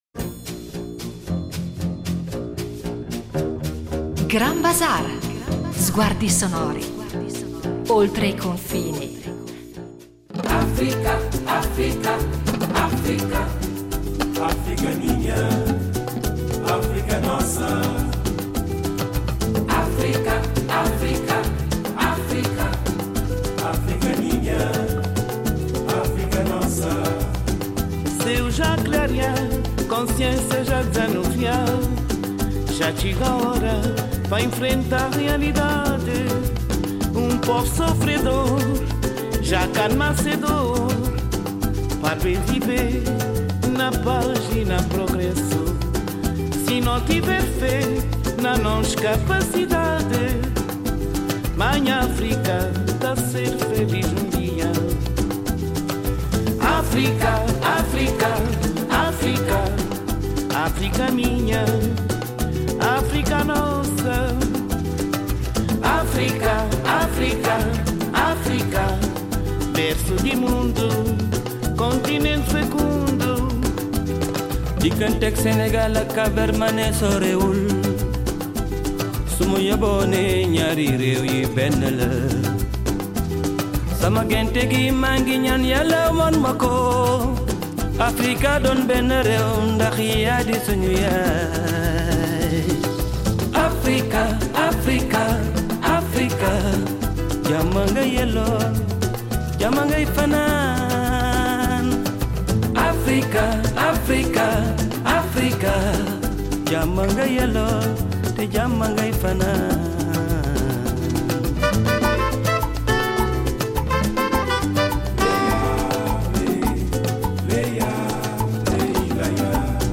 Un viaggio nella musica di Capo Verde tra morna, funaná e coladeira
Attraverso ascolti guidati, racconti e contesti storici, “Grand Bazaar” ripercorre l’evoluzione della musica capoverdiana dalle sue radici popolari fino alla diffusione internazionale, mettendo in luce figure emblematiche e voci contemporanee che continuano a rinnovarne il linguaggio senza tradirne lo spirito.